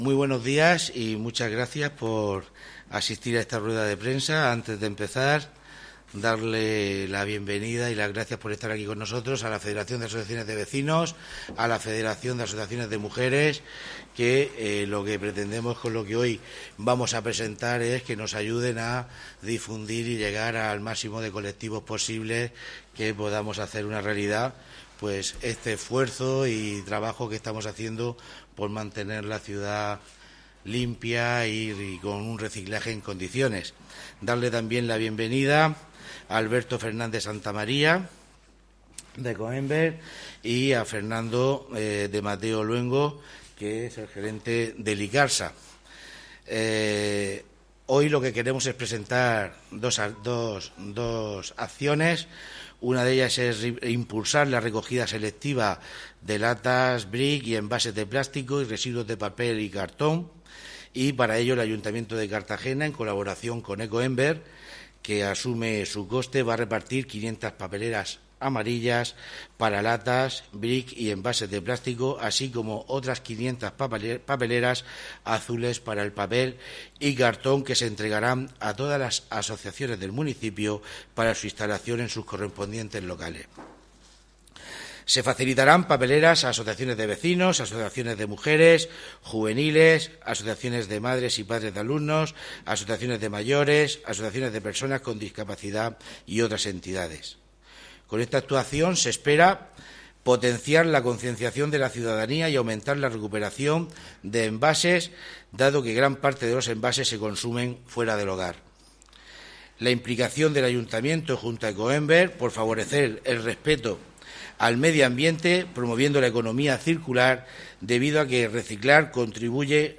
Audio: Presentaci�n campa�a de reciclaje y limpieza de micciones caninas (MP3 - 22,38 MB)